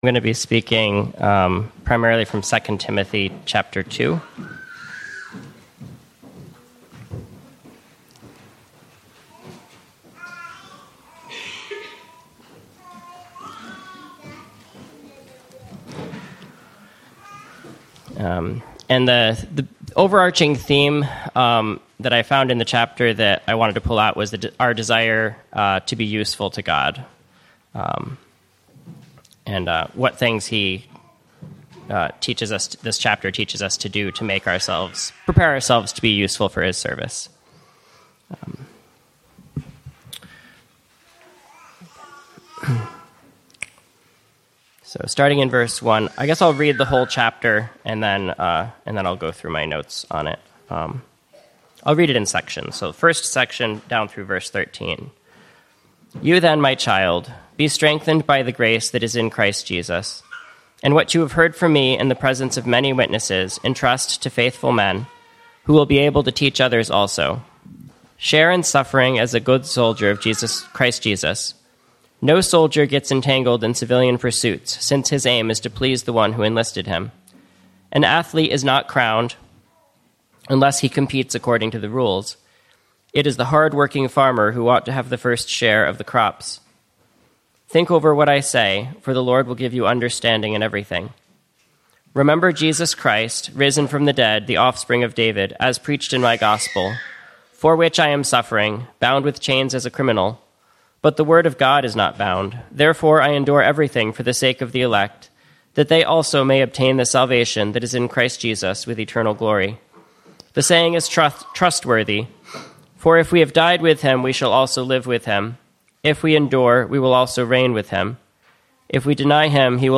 [00:00:00] Introduction: The sermon’s theme